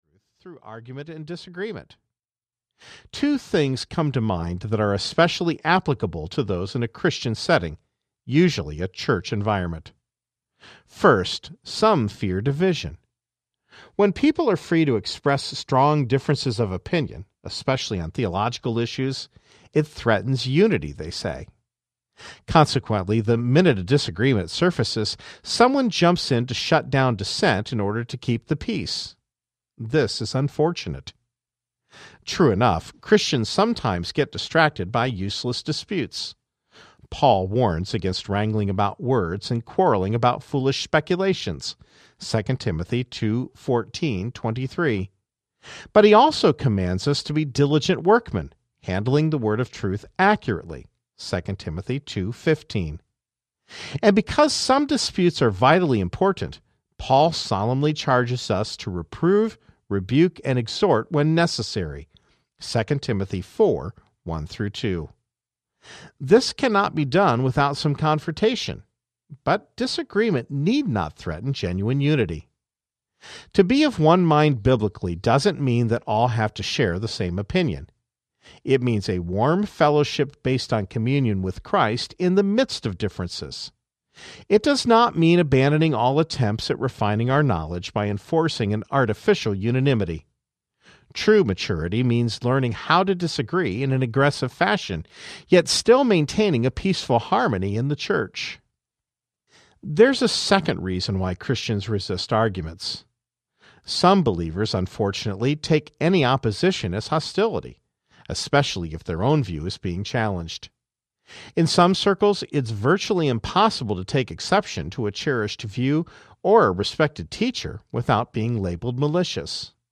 Tactics Audiobook
6.05 Hrs. – Unabridged